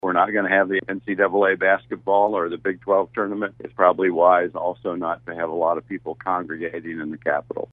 Kansas 22nd District Senator Tom Hawk spoke to KMAN Friday about the possibility of the Kansas legislature closing down in response to the COVID-19 outbreak.